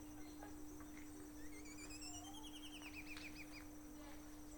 Green-backed Becard (Pachyramphus viridis)
Sex: Male
Life Stage: Adult
Detailed location: Vivero municipal
Condition: Wild
Certainty: Observed, Recorded vocal